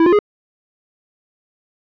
フリー効果音：システム５
システム系効果音の第５弾！トラップに引っかかったときの音にぴったり？